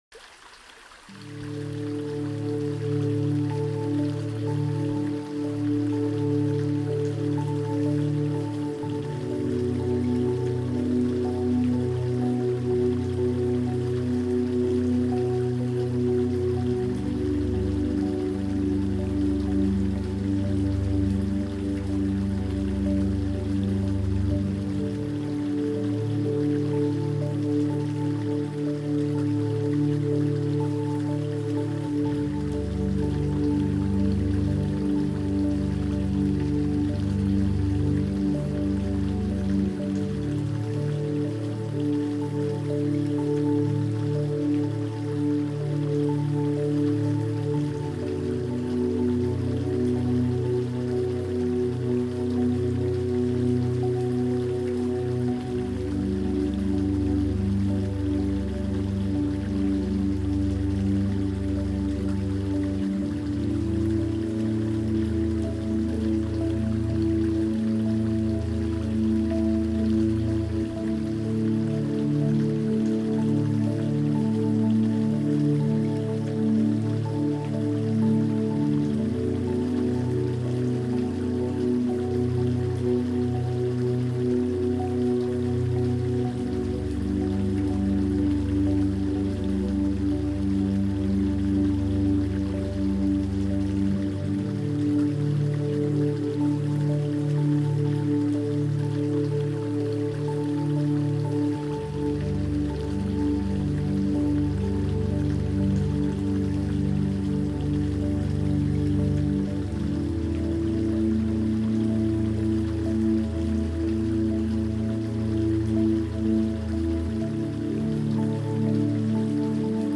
LAqpvUGuueu_musica-relajante-corta-naturaleza-musica-relajante-corta-duracion.mp3